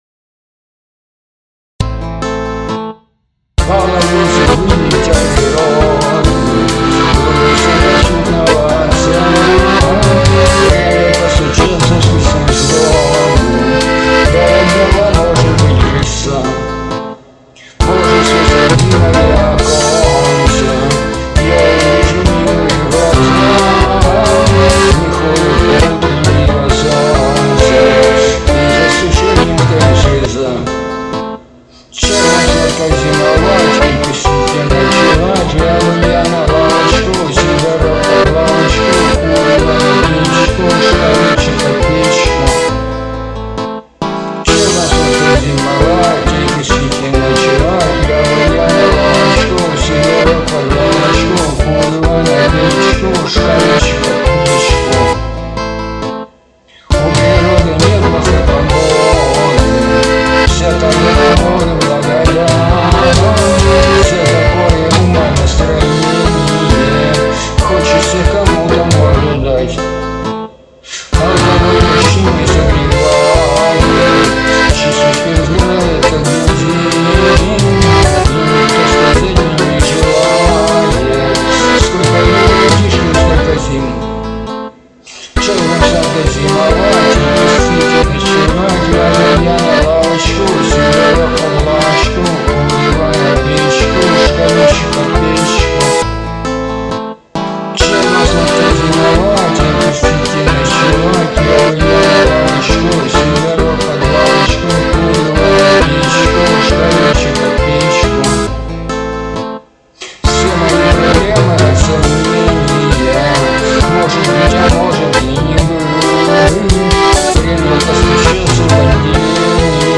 • Жанр: Кантри